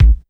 Kick_11.wav